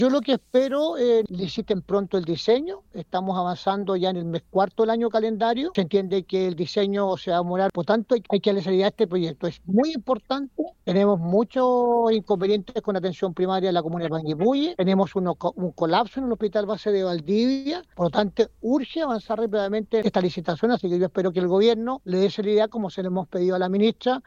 El alcalde de Panguipulli y militante socialista, Rodrigo Valdivia, indicó que era incomprensible la decisión del gobierno anterior y llamó a las actuales autoridades a agilizar el diseño del hospital.